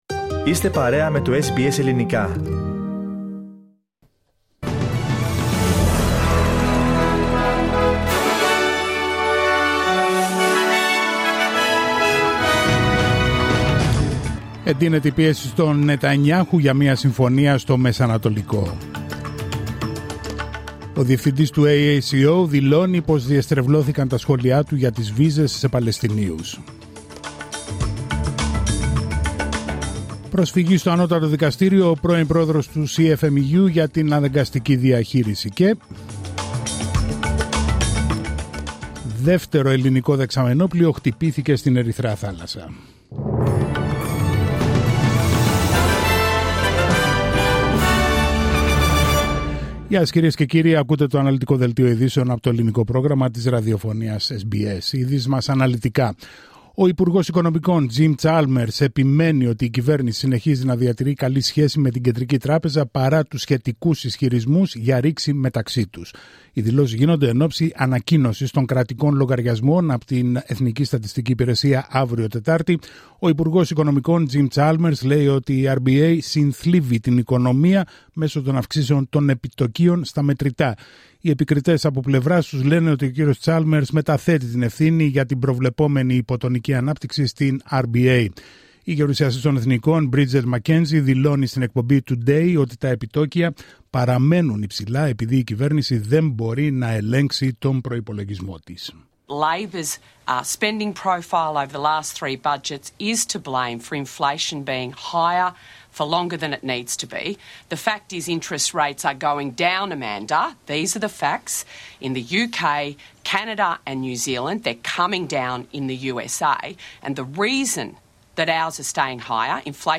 Δελτίο ειδήσεων Τρίτη 3 Σεπτεβρίου 2024